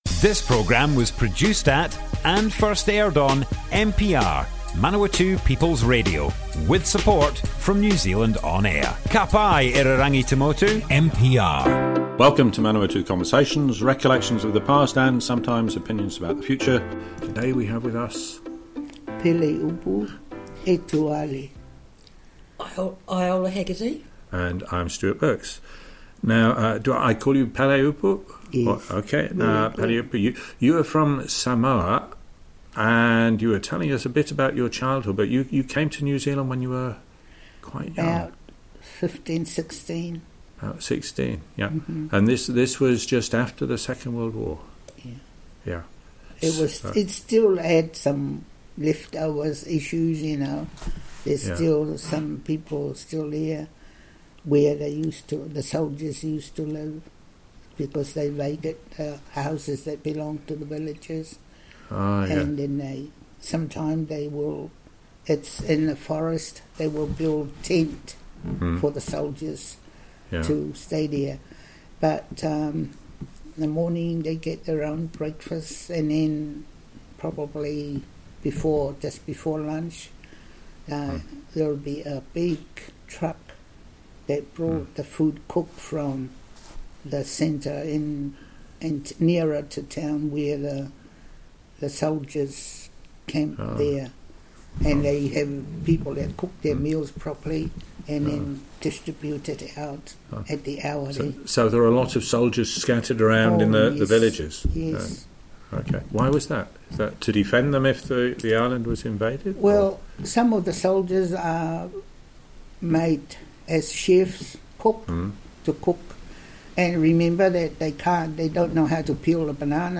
Manawatu Conversations More Info → Description Broadcast on Manawatu People's Radio, 18th May 2021.
oral history